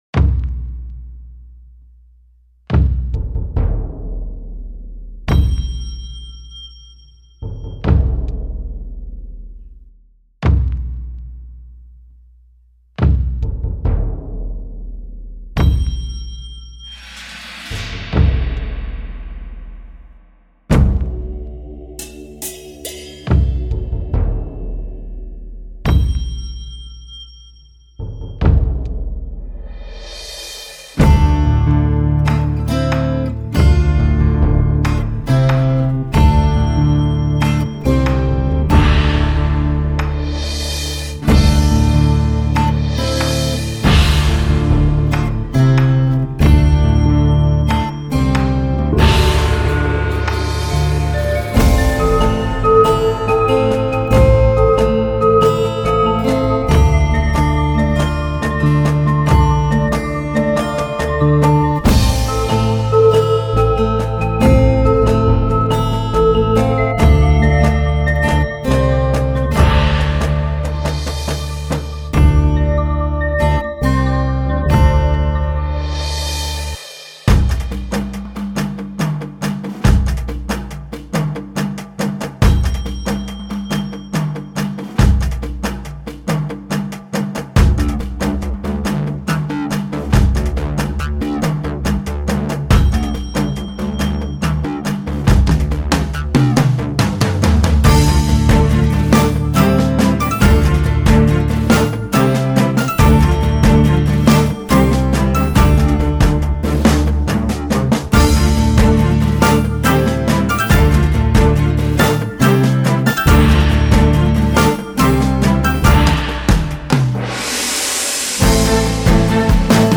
Snippet 4 – Musik für Artisten